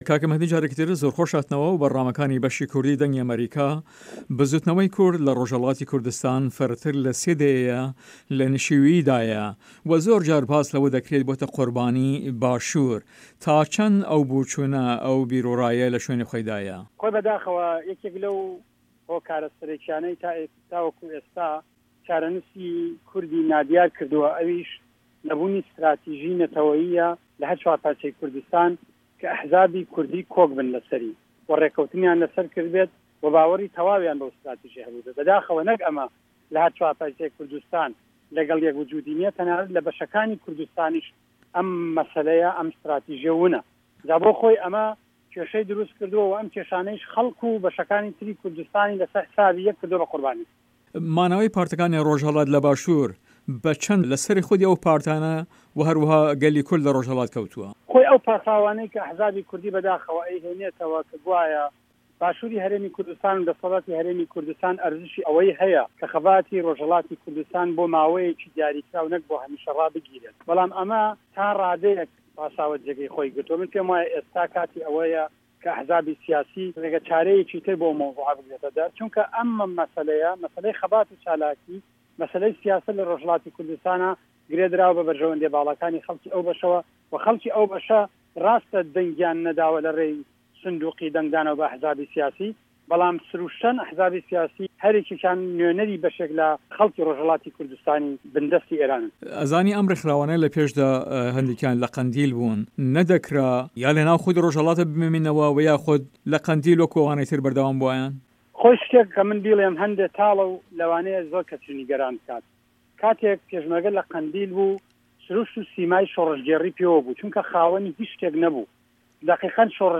هه‌رێمه‌ کوردیـیه‌کان - گفتوگۆکان
له‌ هه‌ڤپه‌ێڤینێکدا له‌گه‌ڵ به‌شی کوردی ده‌نگی ئه‌مه‌ریکا